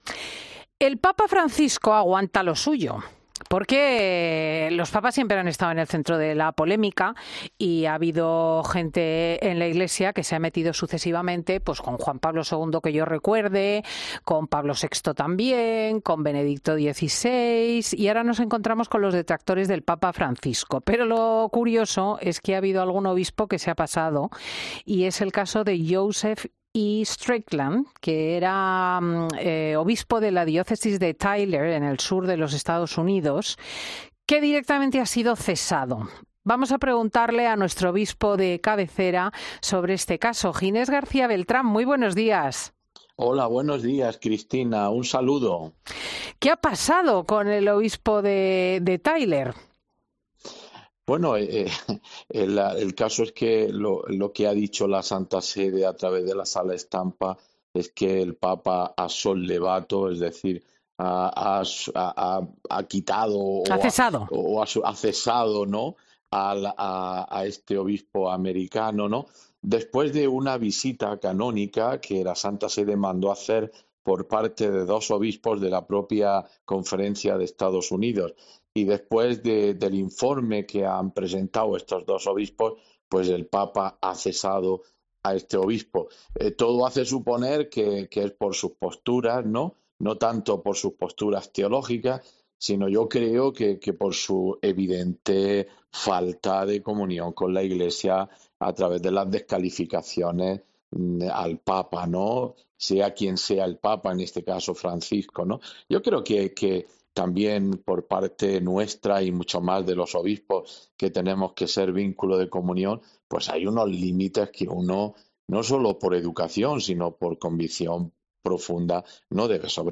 El obispo de cabecera de Fin de Semana se pronuncia sobre la destitución del obispo estadounidense Joseph Strickland, crítico con la autoridad de la jerarquía eclesial